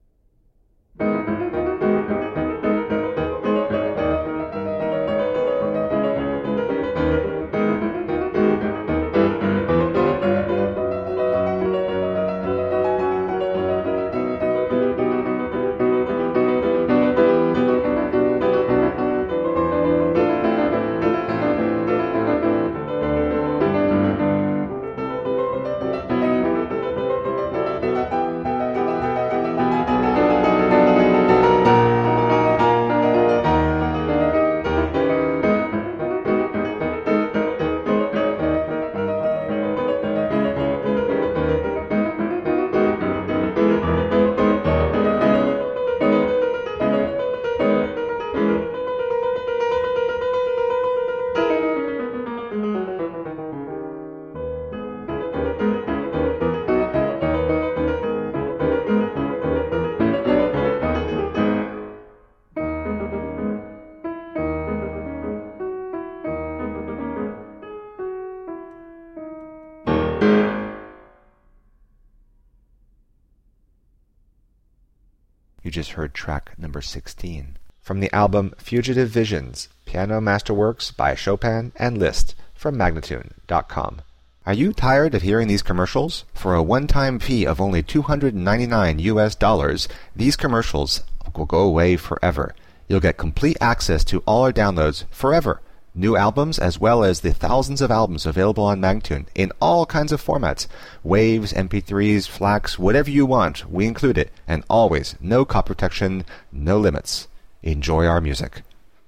Dazzling classical pianist.